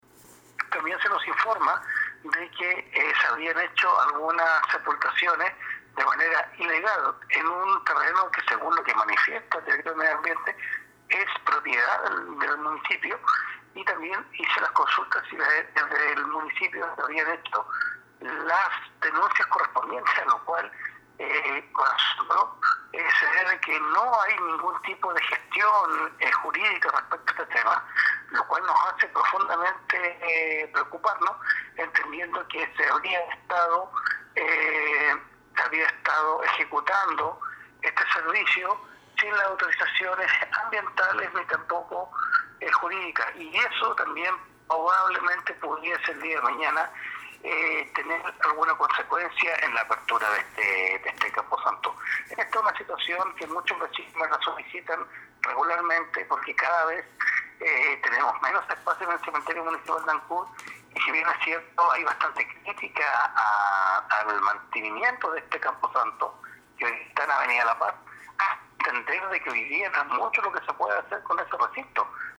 También requirió informes respecto de las presentaciones legales ante tribunales por parte de la municipalidad, ante las actuaciones irregulares acontecidas en el sitio del sector Pupelde, dijo el concejal Andrés Ibáñez.